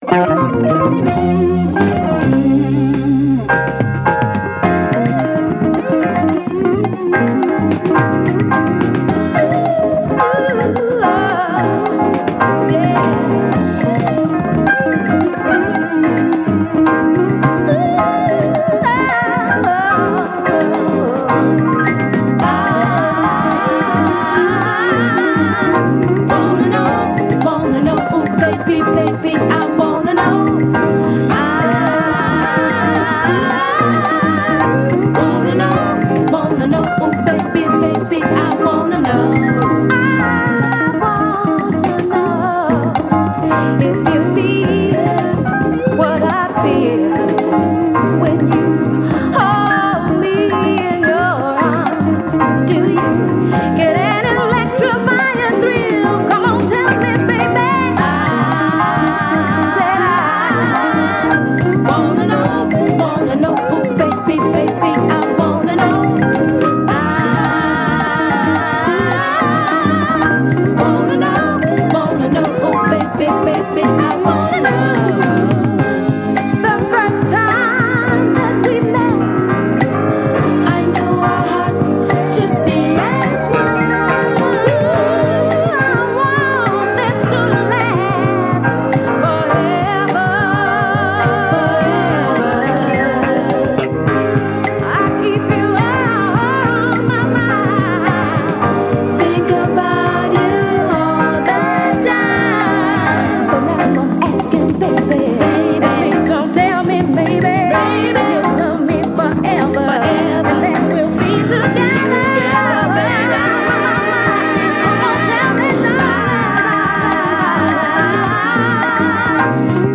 Mid-Tempo-Shuffler
Funk / Soul